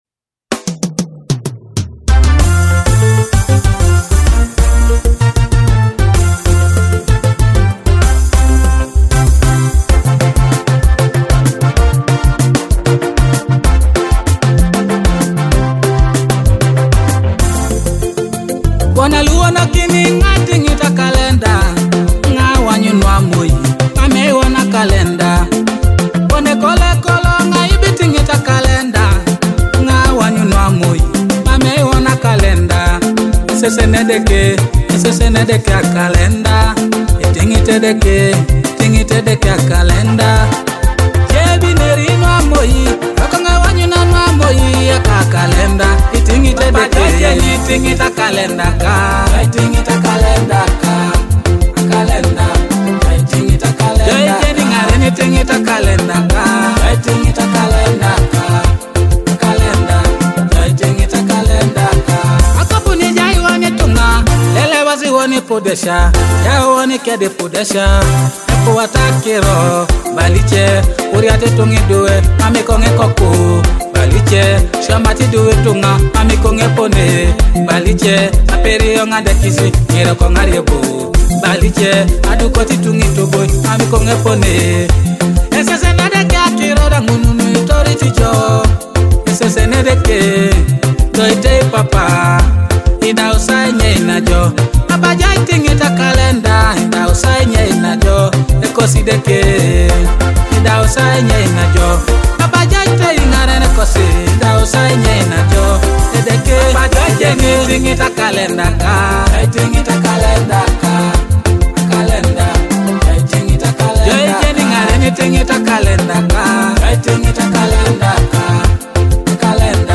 Teso gospel